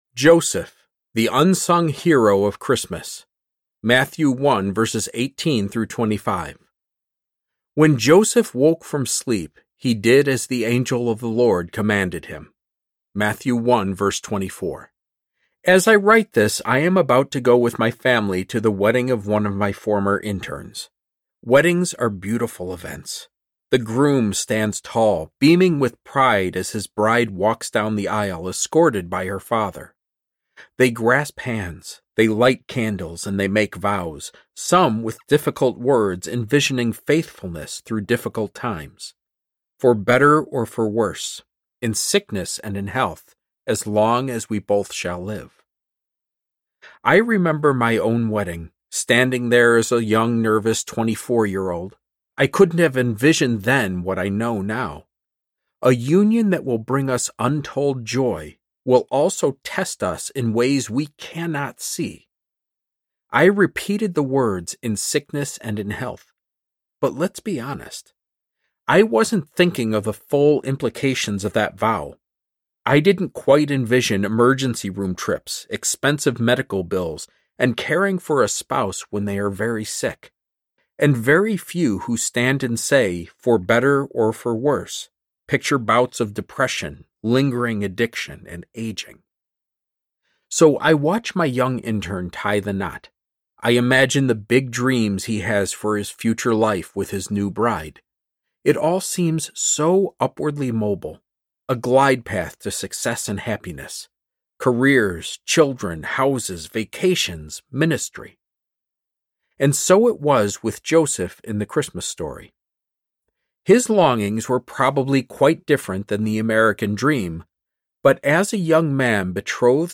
The Characters of Christmas Audiobook
Narrator
4.5 Hrs. – Unabridged